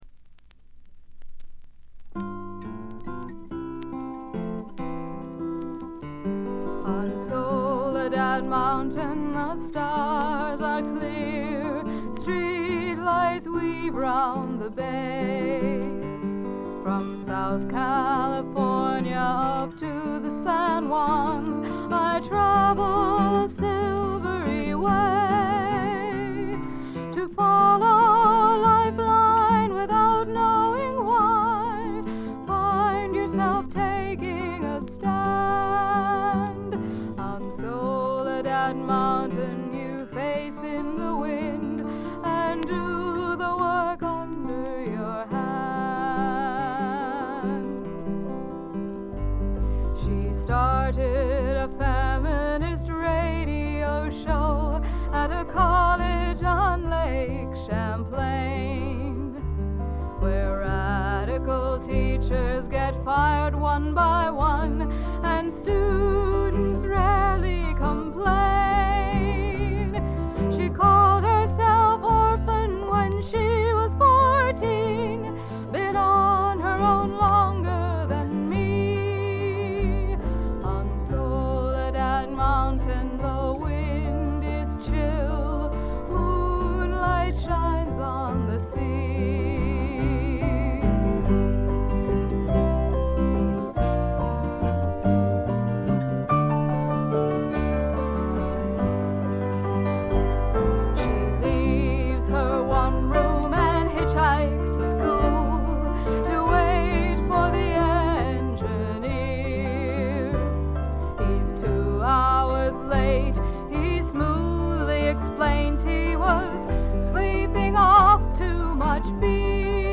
ホーム > レコード：米国 女性SSW / フォーク
弾き語りを中心にごくシンプルなバックが付く音作り、トラッドのアカペラも最高です。
Vocals, Guitar, Autoharp, Recorder